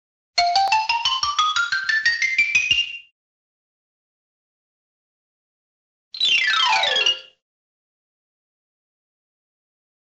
Tiếng đánh phím đàn Piano liên tiếp, liên tục
Thể loại: Hiệu ứng âm thanh
Description: Tiếng đàn piano vang lên dồn dập, từng phím đồ, rê, mi, pha, son được nhấn xuống một cách liên tục, tạo nên một chuỗi âm thanh trầm bổng, trong trẻo và giao thoa nhịp nhàng. Những nốt nhạc này vang lên liền nhau như làn sóng âm thanh, như thể người nghệ sĩ đang "nói chuyện" với cây đàn bằng một ngôn ngữ âm nhạc uyển chuyển và linh hoạt.
tieng-danh-phim-dan-piano-lien-tiep-lien-tuc-www_tiengdong_com.mp3